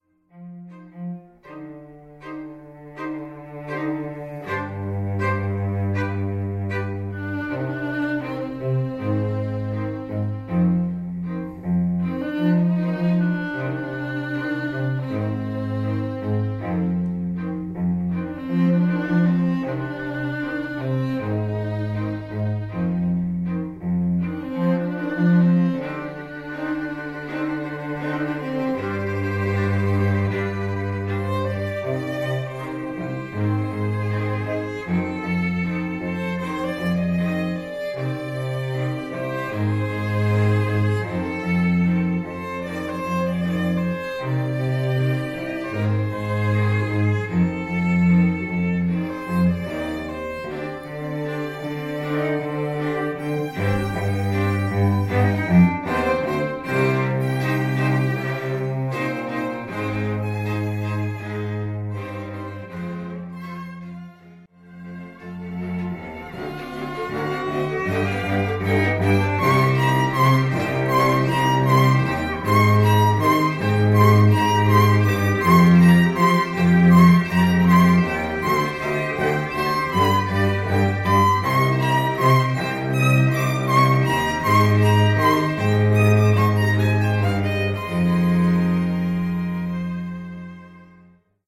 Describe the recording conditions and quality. • Fully acoustic - can play anywhere